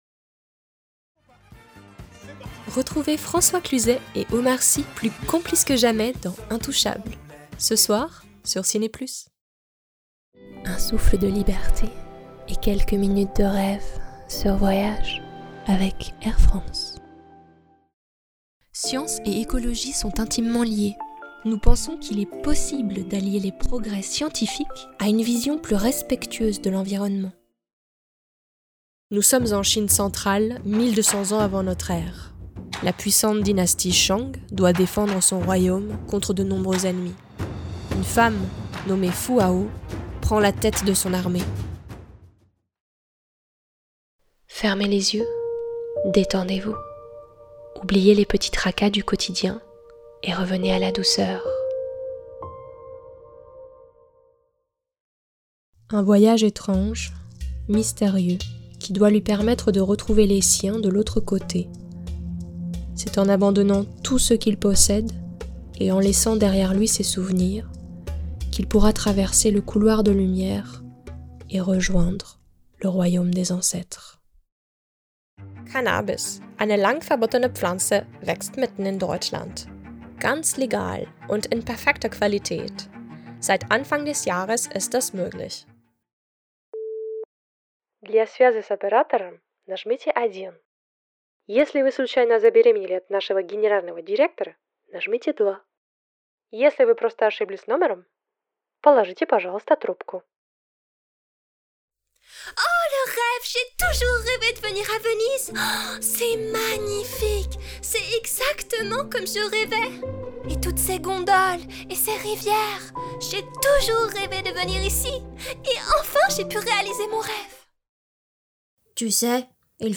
bande démo voix-off
Comédienne Voix off Narratrice